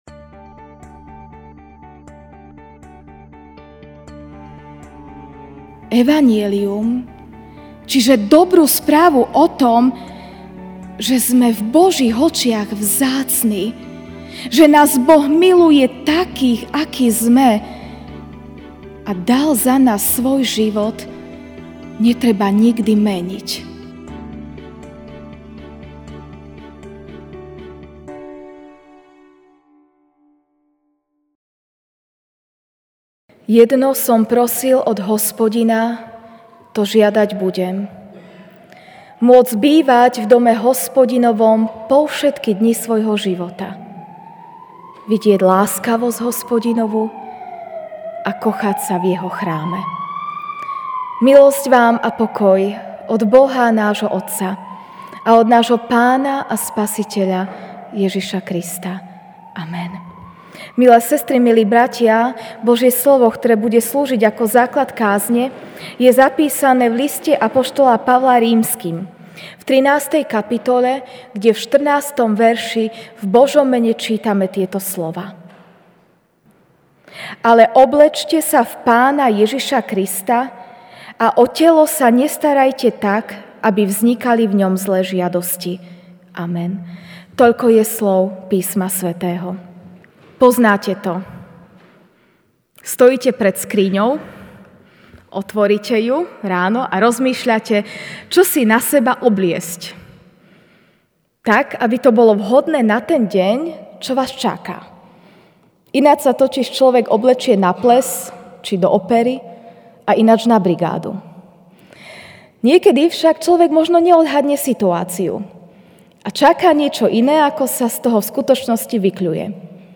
sep 01, 2019 Oblečme si Pána Ježiša MP3 SUBSCRIBE on iTunes(Podcast) Notes Sermons in this Series Večerná kázeň: Oblečme si Pána Ježiša (R 13, 14) Ale oblečte sa v Pána Ježiša Krista a o telo sa nestarajte tak, aby vznikali v ňom zlé žiadosti.